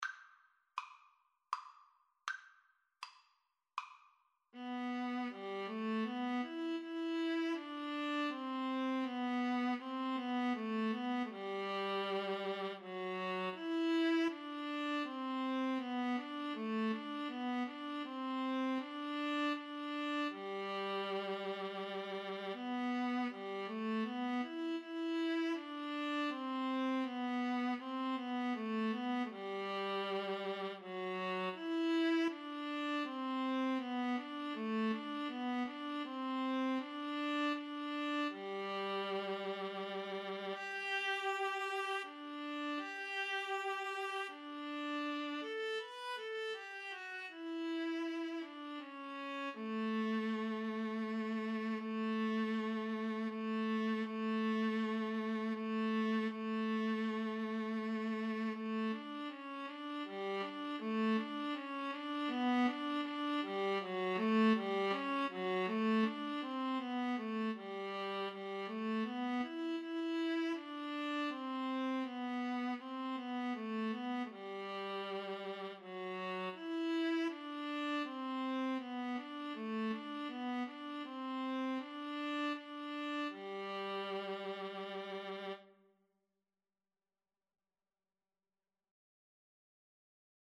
Play (or use space bar on your keyboard) Pause Music Playalong - Player 1 Accompaniment transpose reset tempo print settings full screen
Andante
G major (Sounding Pitch) (View more G major Music for Violin-Viola Duet )
Classical (View more Classical Violin-Viola Duet Music)